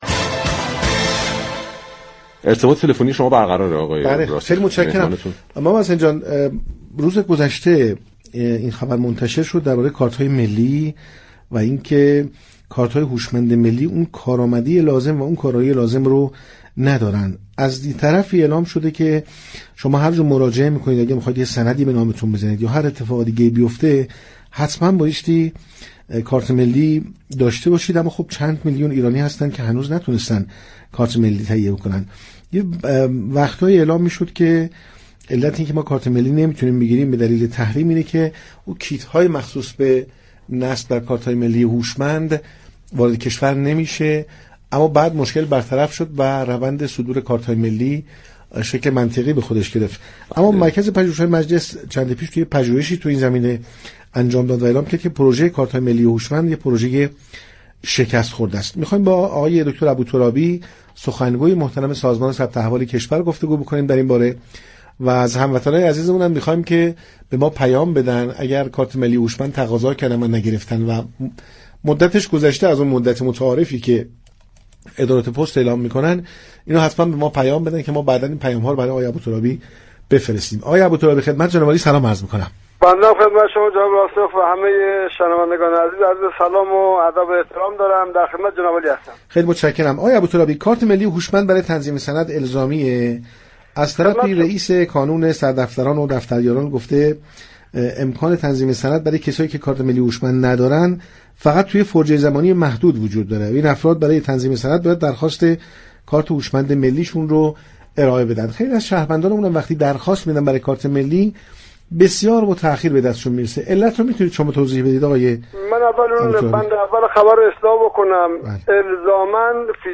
در گفتگو با برنامه پارك شهر